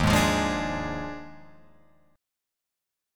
D#M7sus2sus4 chord